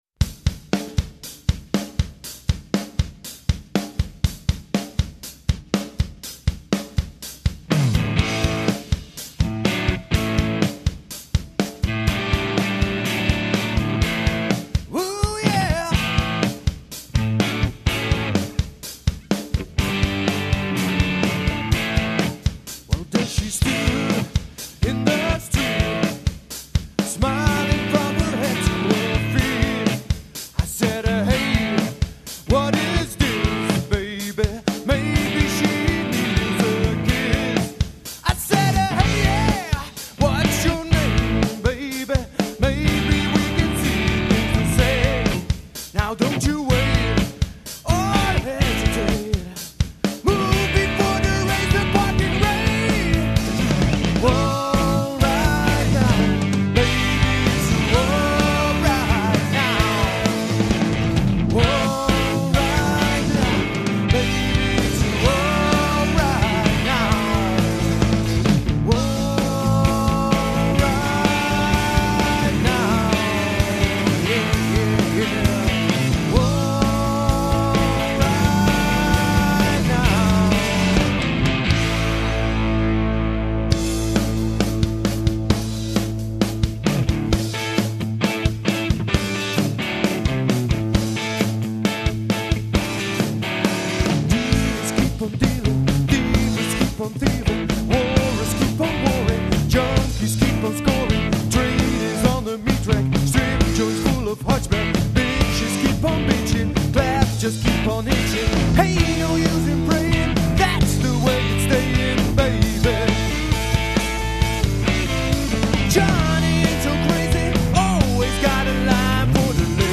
Zang
Gitaar
Drums